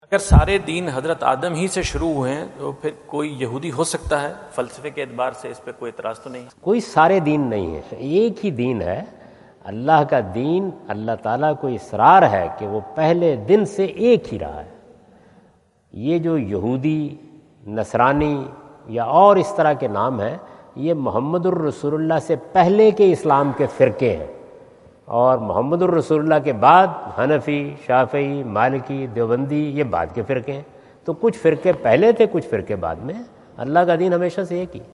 Javed Ahmad Ghamidi answer the question about "If all religions started from Abraham then why we criticise Jews?" During his US visit at Wentz Concert Hall, Chicago on September 23,2017.